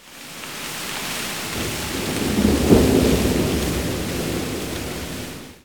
2506L RAINFX.wav